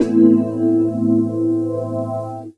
C GTR 1.wav